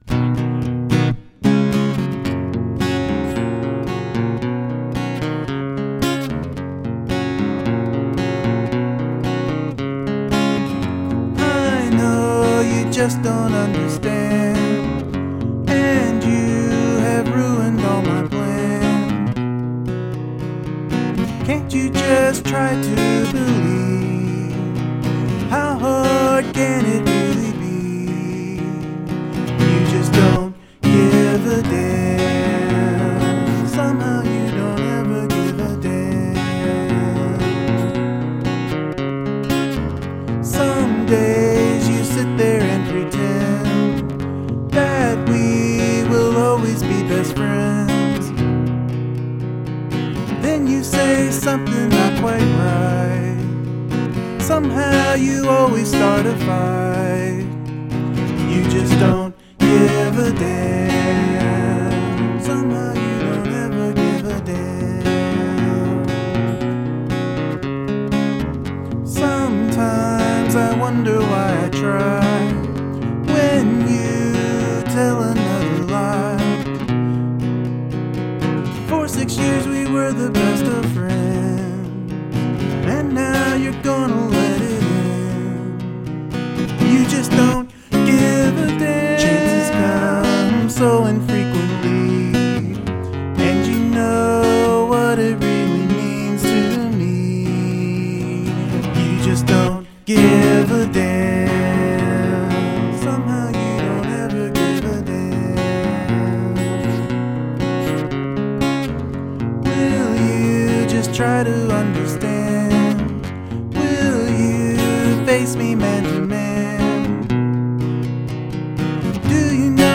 i like that walkin pickin guitar part, very cool.!
Vocals sound solid and I like the harmony parts.